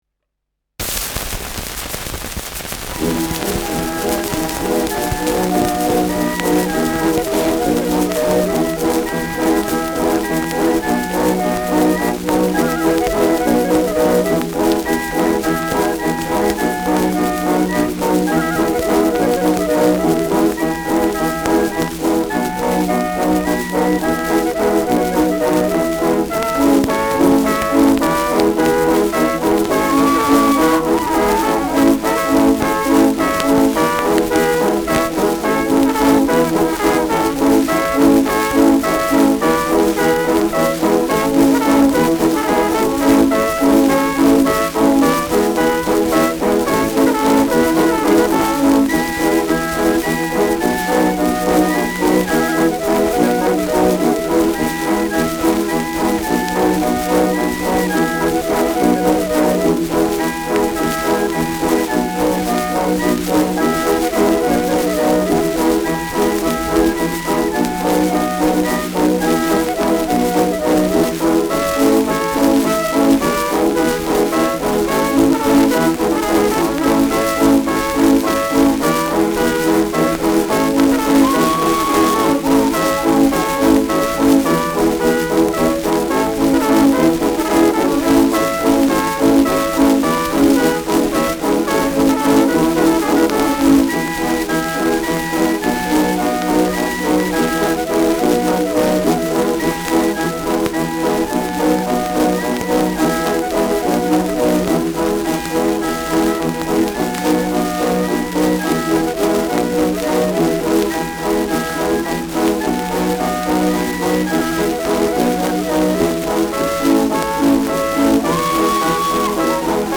Schellackplatte
präsentes Rauschen : abgespielt : leiert : gelegentliches Knacken
[München?] (Aufnahmeort)